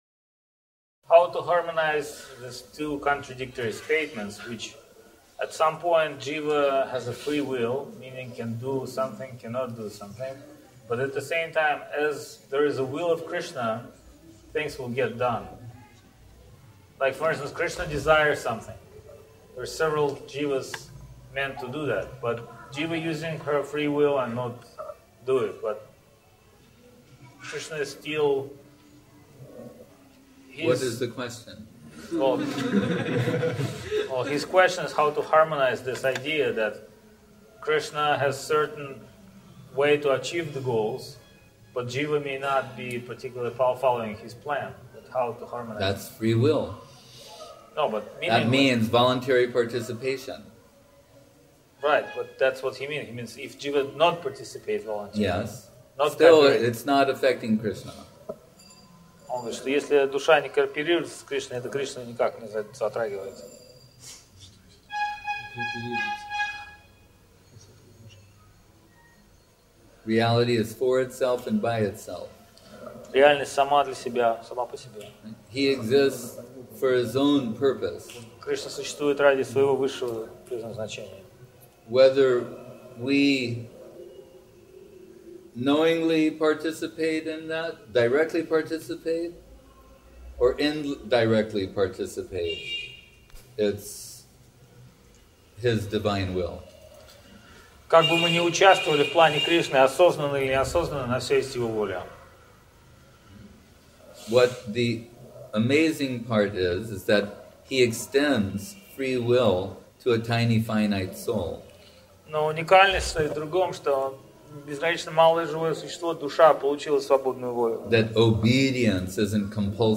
Место: ШЧСМатх Навадвип